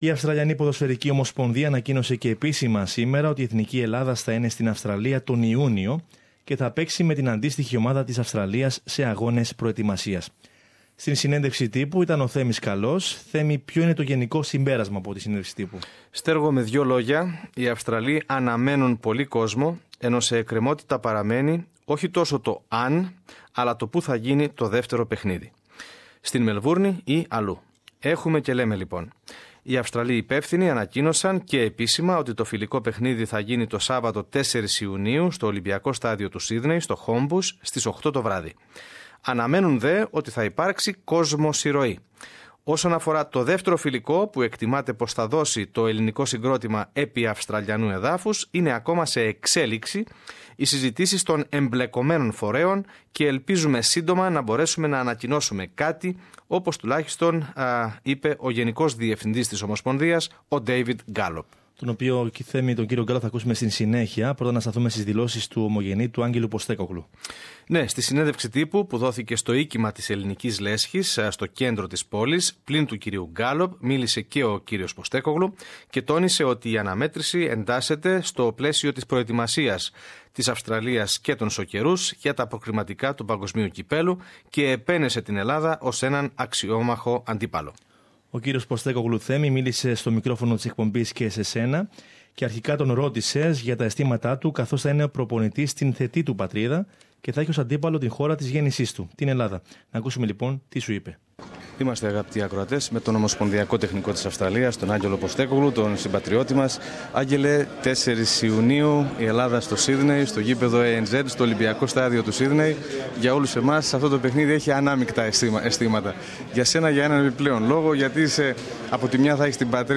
Το ρεπορτάζ περιέχει επίσης συνεντεύξεις με τον Γενικό Διευθυντή της Αυστραλιανής ποδοσφαιρικής Ομοσπονδίας, David Gallop, και τον γενικό Πρόξενο της Ελλάδας στο Σύδνεϋ, Δρ. Σταύρο Κυρίμη.